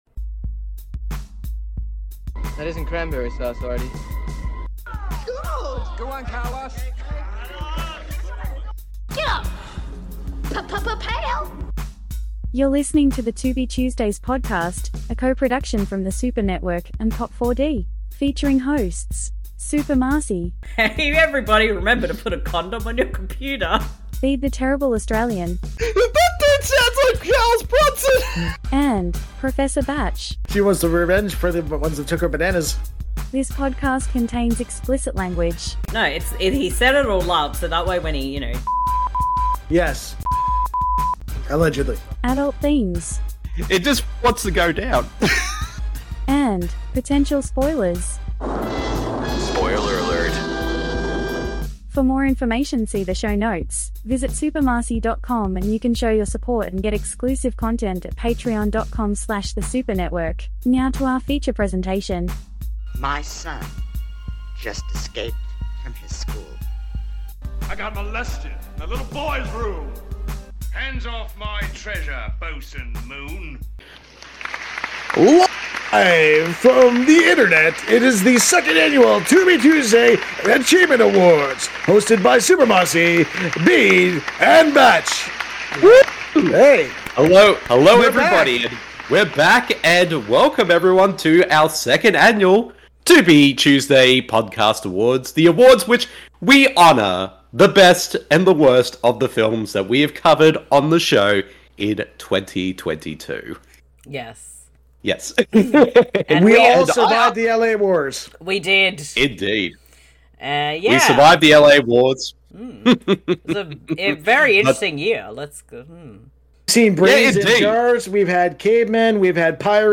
There are many awards given out, some acceptance speeches and special guests!